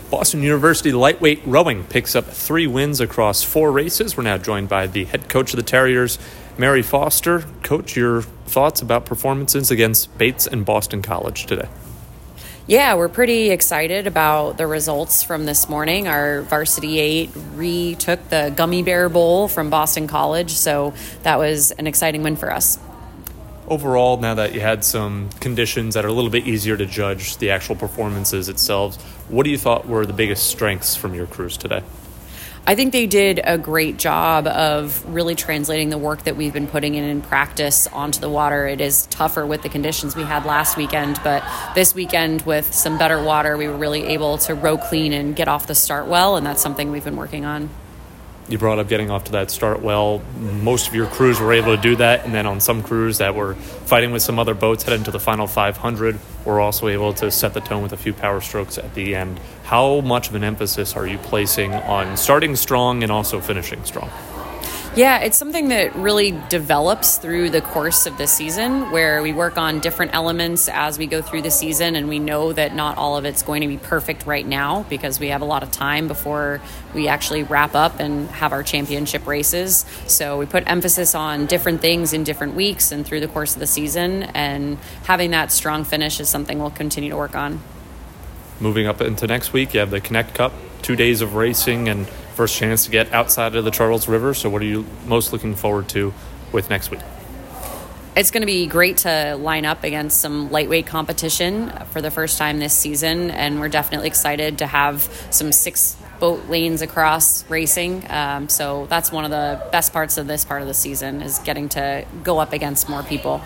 Bates & Boston College Postrace Interview
LTROW_Bates_BC_Postrace.mp3